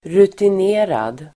Uttal: [rutin'e:rad]